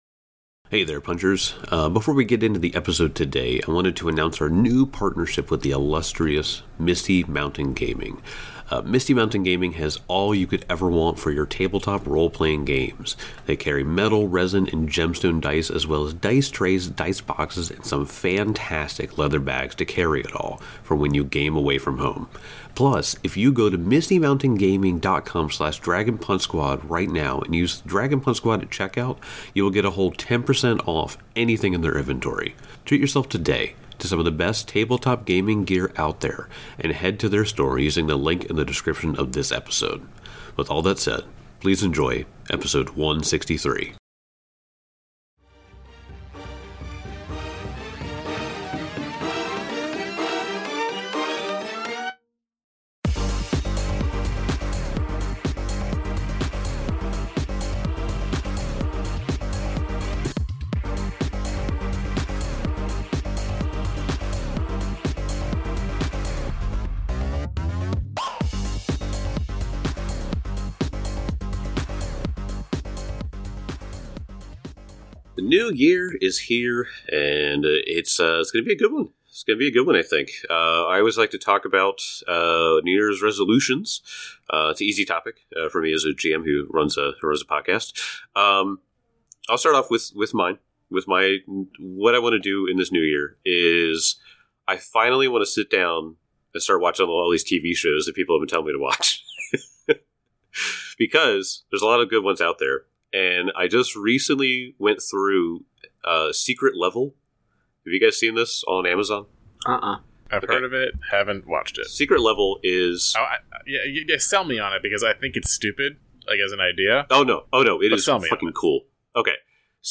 Actual play podcast of the Pathfinder 2e, Age of Ashes adventure path produced by Paizo. Five nerdy best friends who love to play pretend with dice!